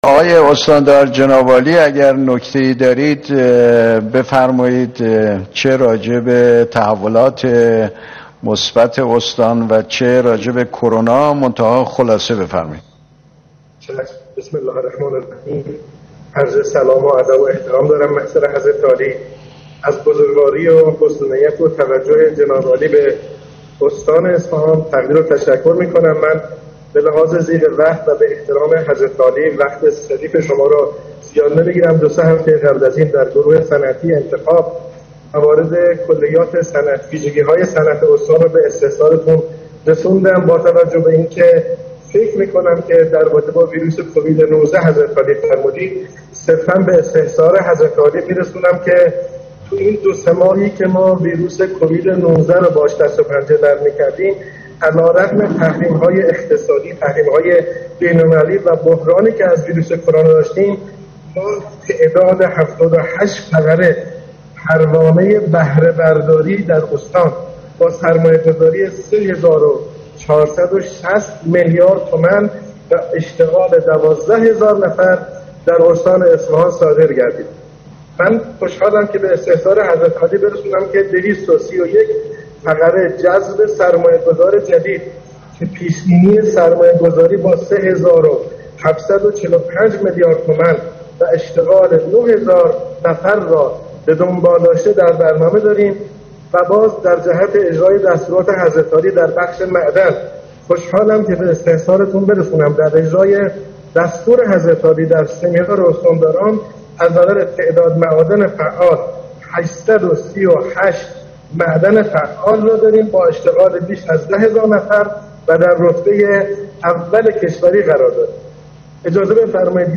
اصفهان- ایرنا- عباس رضایی استاندار اصفهان روز پنجشنبه در آیین بهره برداری 2 طرح صنعتی که به صورت ویدئوکنفرانس با دستور رییس جمهوری افتتاح شد، گزارشی دستاوردهای صنعتی استان در این پادکست ارایه کرد.